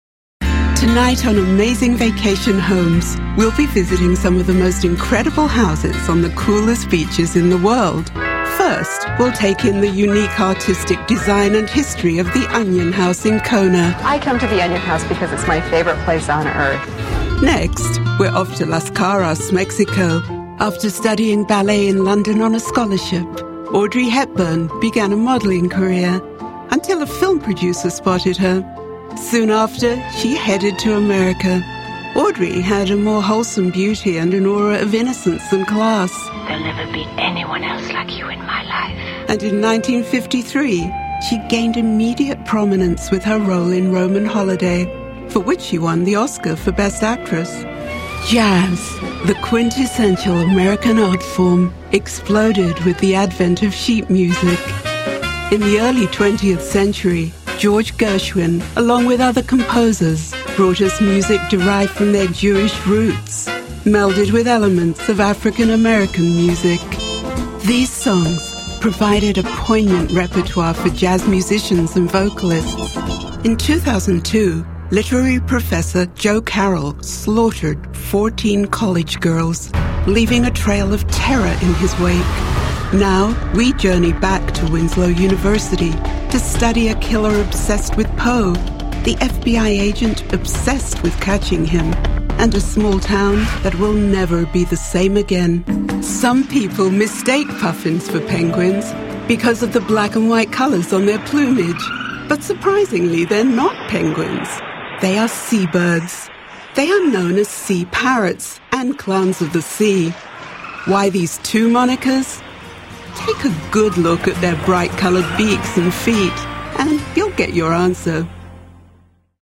Voice Artist living in Los Angeles working in British and Global Transatlantic-Mid-Atlantic English
Sprechprobe: Industrie (Muttersprache):